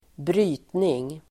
Uttal: [²br'y:tning]